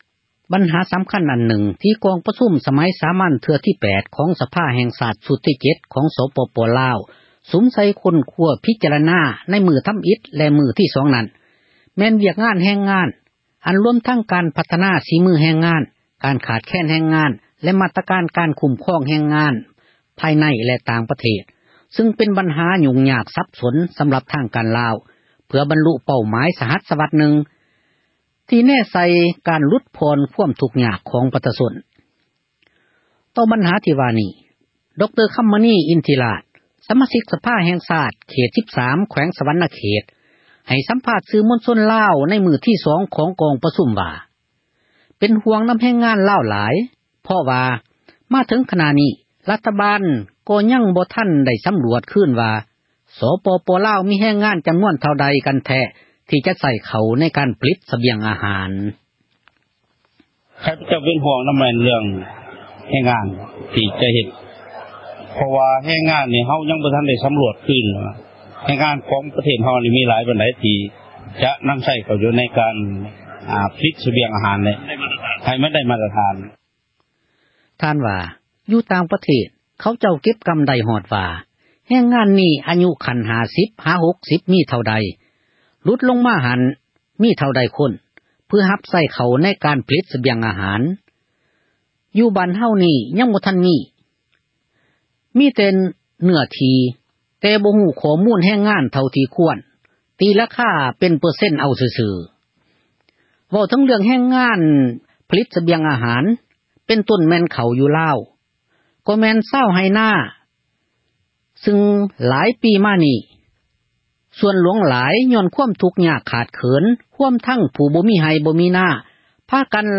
ດຣ. ຄໍາມະນີ ອິນທິລາດ ສະມາຊິກ ສະພາ ແຫ່ງຊາດ ເຂດ 13 ແຂວງ ສວັນນະເຂດ ໃຫ້ ສໍາພາດ ຕໍ່ ສື່ມວນຊົນ ລາວ ວ່າ ເປັນ ຫ່ວງນໍາ ແຮງງານ ລາວ ເພາະວ່າ ຣັຖບາລ ຍັງບໍ່ໄດ້ ສໍາຣວດ ວ່າ ໃນລາວ ມີແຮງງານ ຈໍານວນ ເທົ່າໃດ ທີ່ຈະໃຊ້ ເຂົ້າໃນ ການຜລິດ ສະບຽງ ອາຫານ: